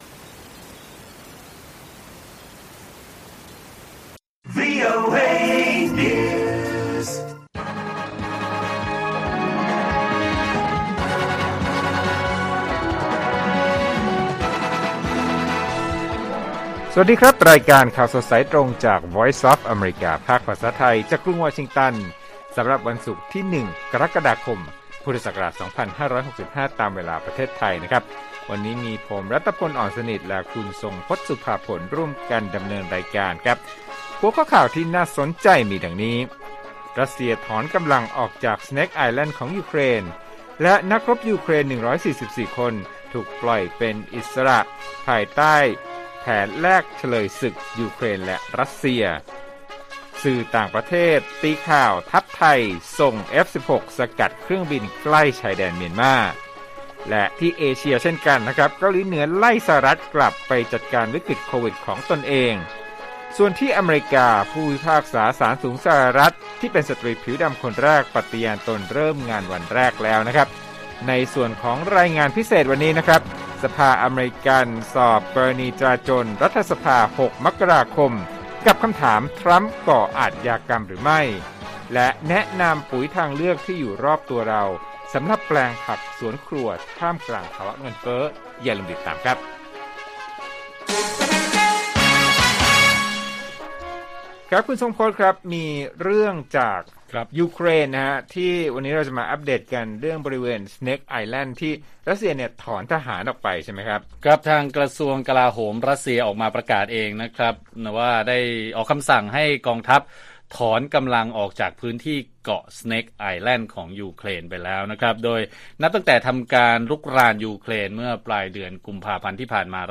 ข่าวสดสายตรงจากวีโอเอไทย 8:30–9:00 น. วันที่ 1 ก.ค. 2565